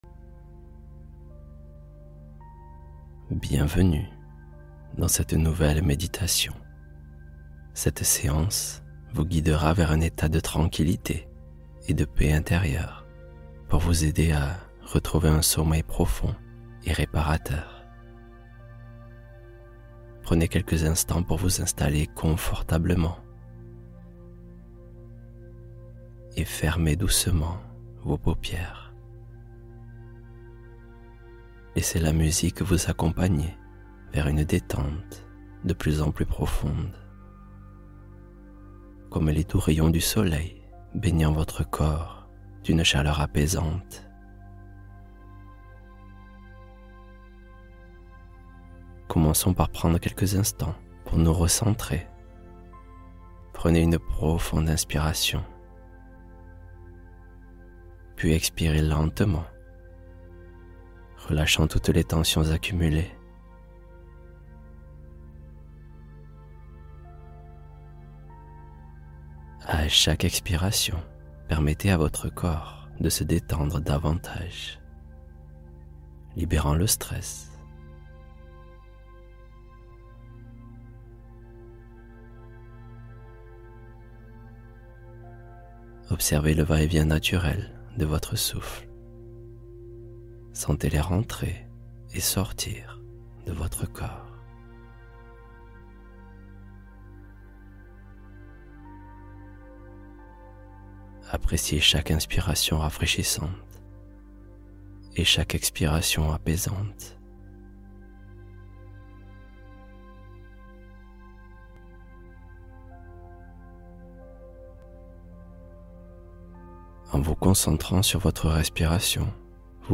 Histoire Guidée pour S'Endormir : La Solution Contre l'Anxiété Nocturne